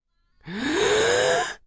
夏天的声音第二集 " 卡通吸气
描述：以卡通的方式深呼吸
标签： 最小 实验 打击乐器 电子 AMP-VST 虚拟-AMP 处理 声音设计 安培建模 毛刺 点击 放大器 arifact Revalver-III 噪声 极简
声道立体声